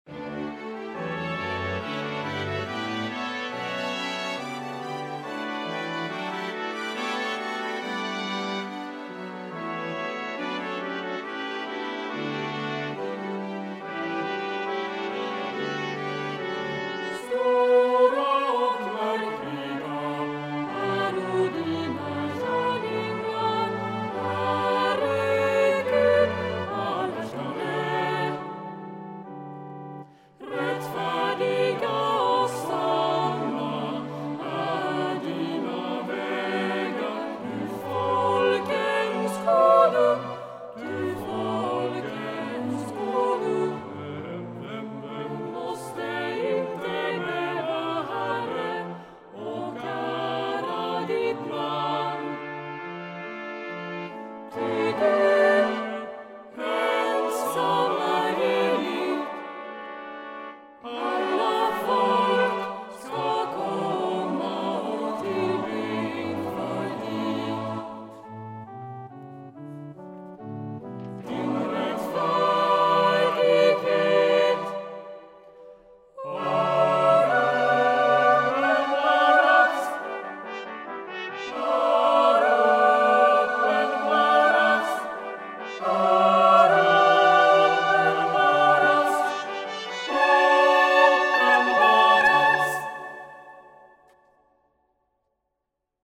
Stora och underbara (gosskören)